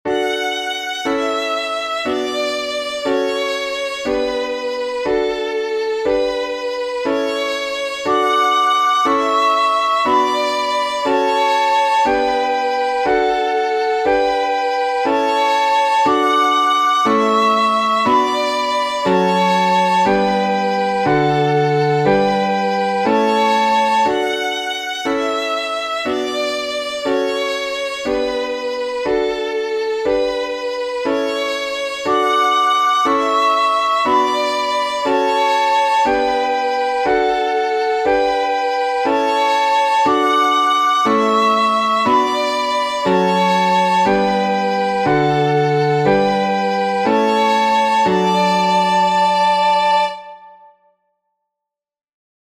This is a three-voice canon, Pachelbel’s Canon in D major (with F# and C#). The score includes three different instruments (violin, recorder, guitar) so that the three voices can be distinguished. It includes a sequence of chords known as the Canon Chord Progression and it is as follows: D (I) – A (V) – Bm (VIm) – F#m (IIIm) – G (IV) – D (I) – G (IV) – A (V).
Canon 3: complete score
canon_a_tres_voces.mp3